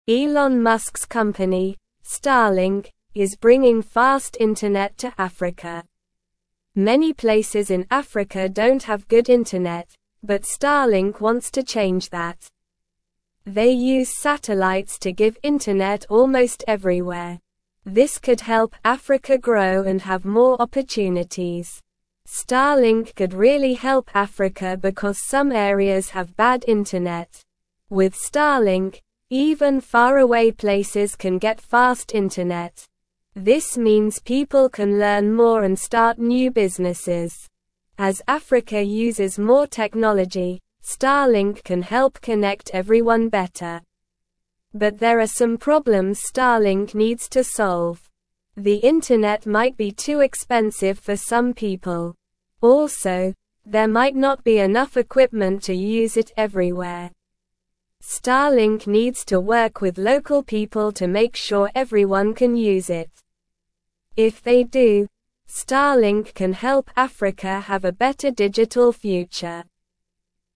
Slow
English-Newsroom-Lower-Intermediate-SLOW-Reading-Starlink-brings-fast-internet-to-Africa-to-help-people.mp3